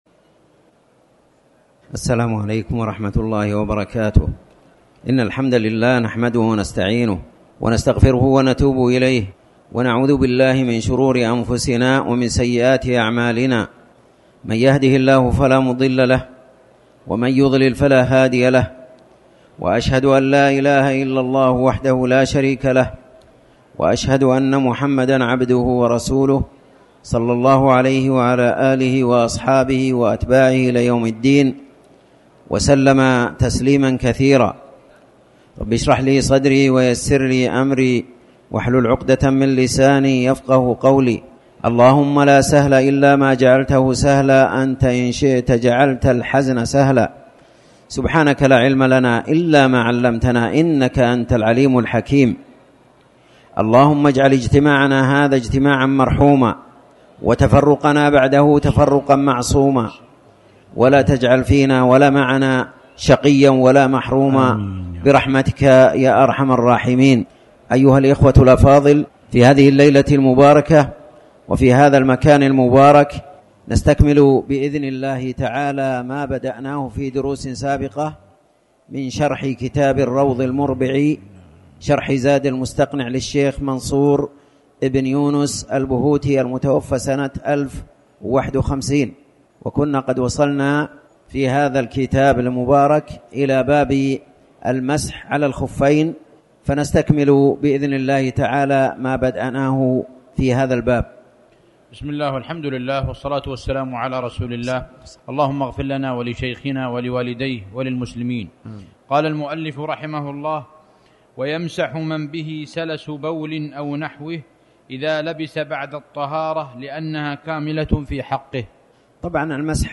تاريخ النشر ٦ صفر ١٤٤٠ هـ المكان: المسجد الحرام الشيخ